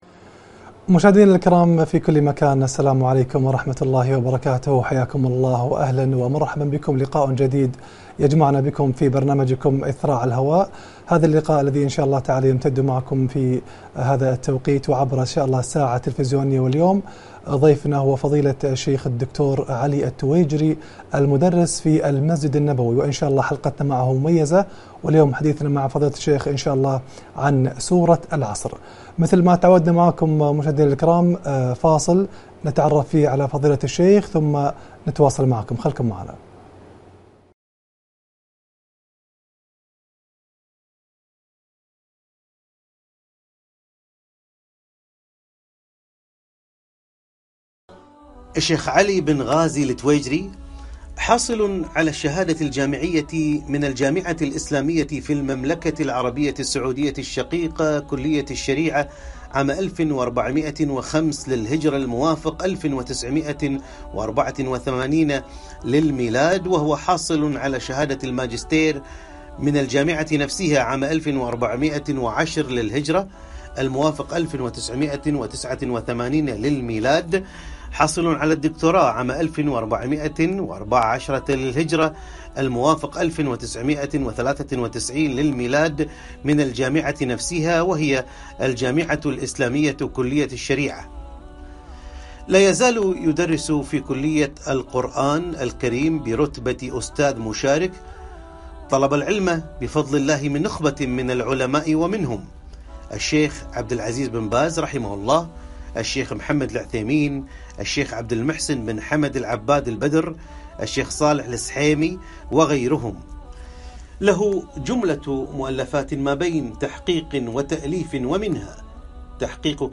لقاء بعنوان : سورة العصر برنامج إثراء على الهواء